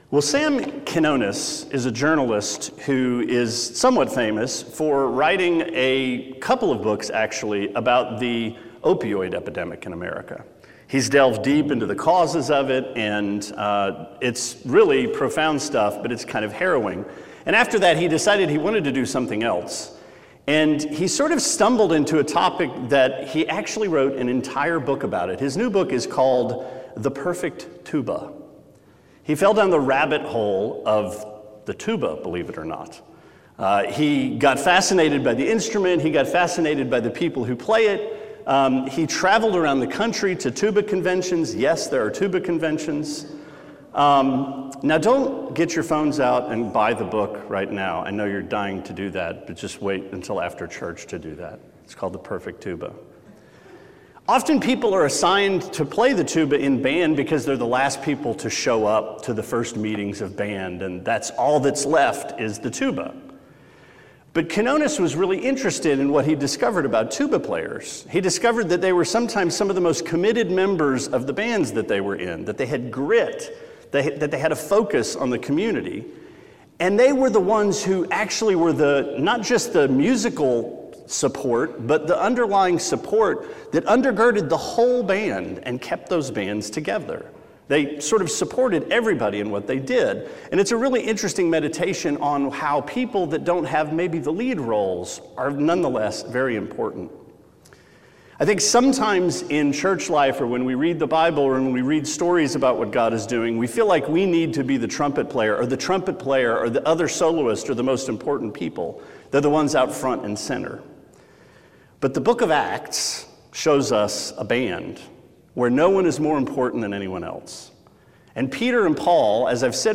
Sermon 12/5: Acts 12: Peter’s Swan Song – Trinity Christian Fellowship